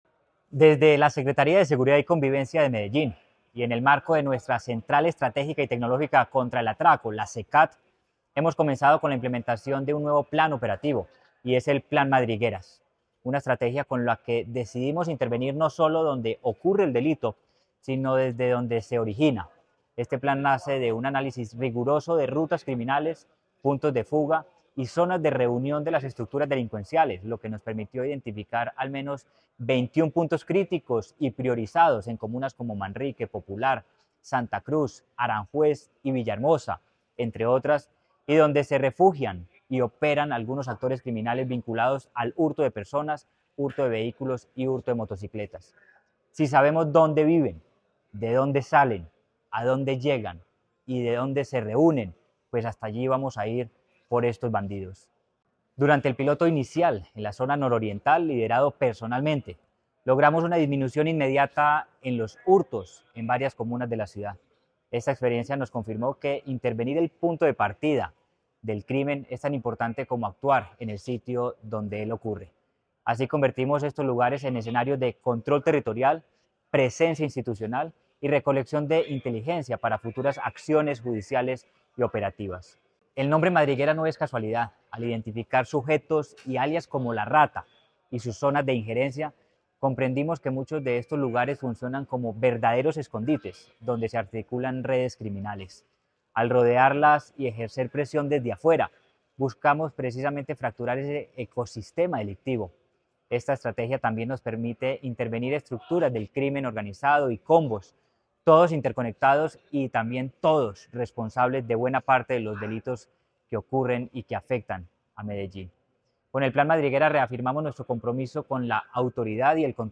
Audio-Declaraciones-del-secretario-de-Seguridad-y-Convivencia-Manuel-Villa-Mejia.mp3